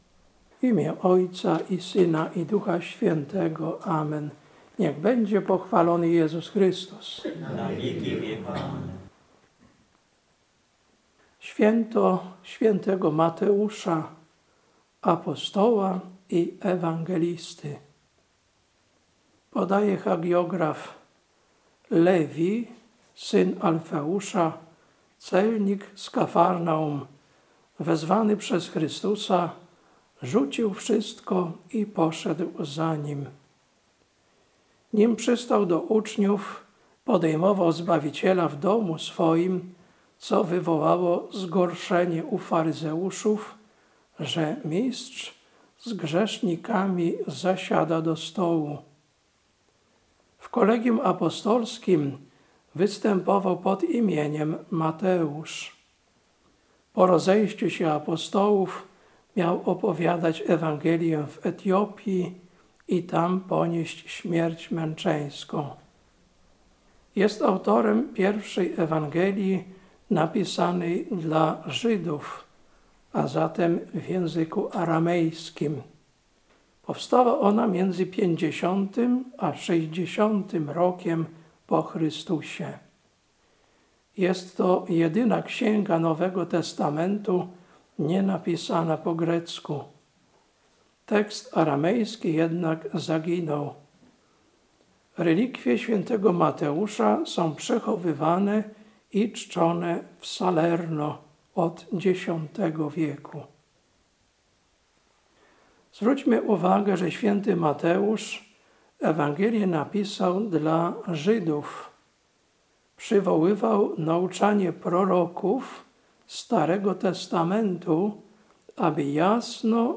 Kazanie na święto św. Mateusza, Apostoła i Ewangelisty, 21.09.2025 Ewangelia: Mt 9, 9-13